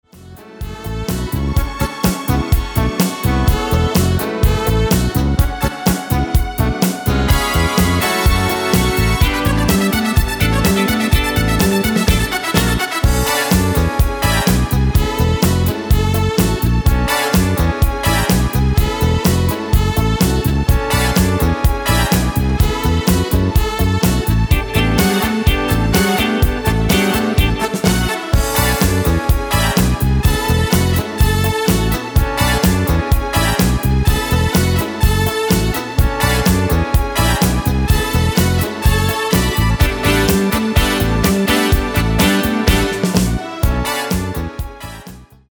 Midi file
Genre: Disco
Key: A
- No vocal harmony tracks
Demo's played are recordings from our digital arrangements.